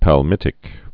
(păl-mĭtĭk, päl-, pä-mĭt-)